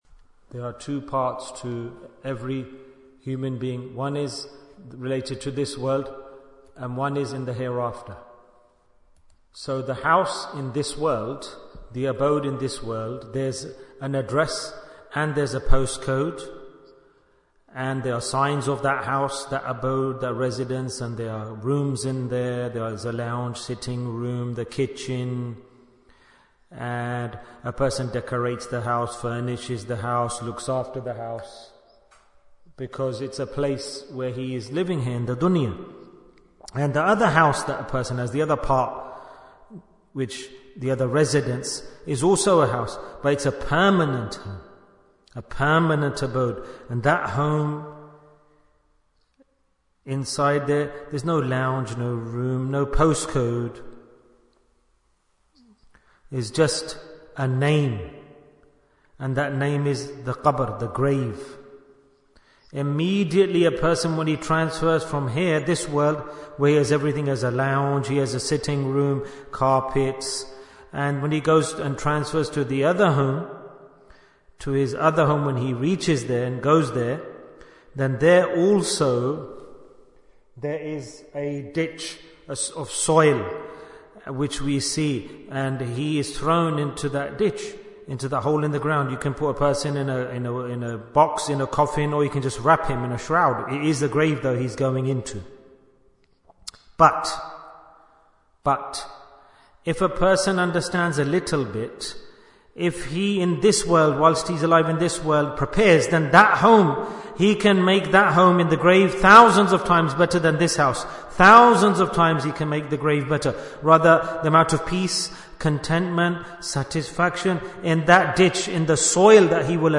Talk before Dhikr 145 minutes2nd December, 2024